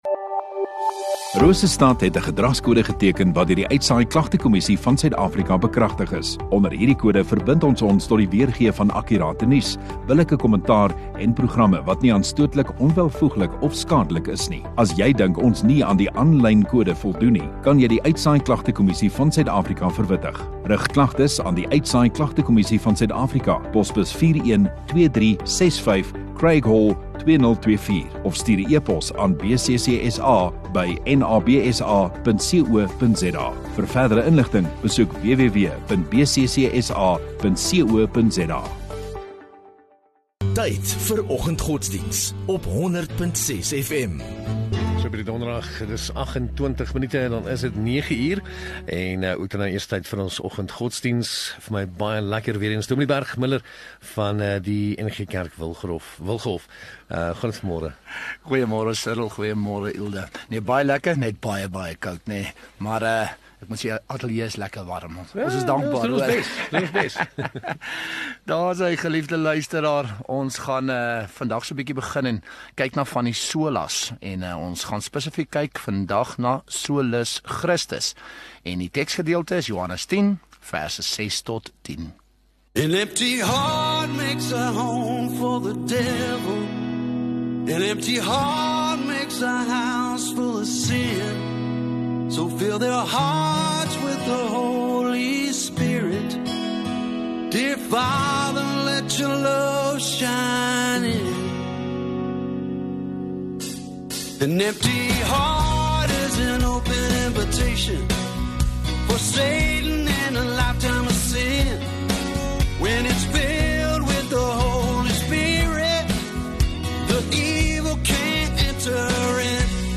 6 Jun Donderdag Oggenddiens